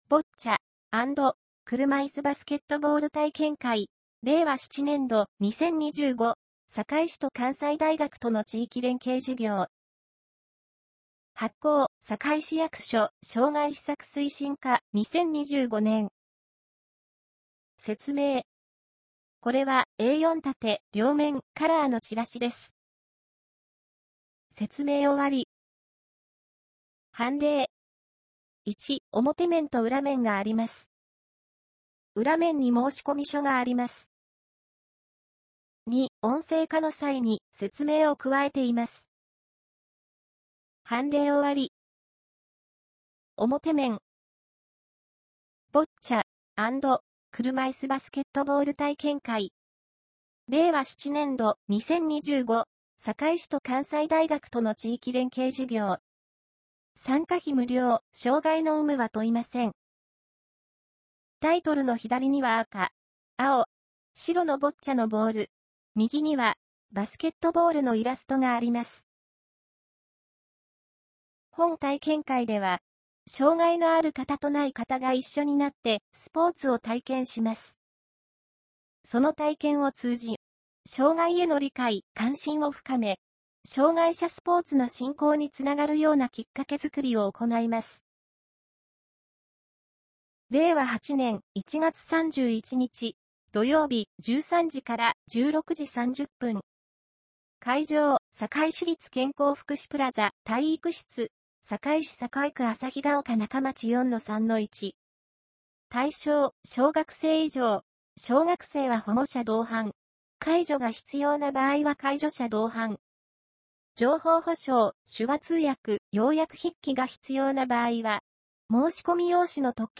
堺市電子申請システムはこちら 「ボッチャ＆車いすバスケットボール体験会」チラシ兼申込書（PDF：1,094KB） 「ボッチャ＆車いすバスケットボール体験」チラシ（合成音声版）（音声：2,445KB） 「ボッチャ＆車いすバスケットボール体験会」チラシ（テキスト版）（ワード：17KB） PDF形式のファイルを開くには、Adobe Acrobat Reader DC（旧Adobe Reader）が必要です。
tirasigouseionnseibann2025.mp3